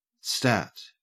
Ääntäminen
IPA : /stæt/